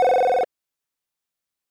dialing01.mp3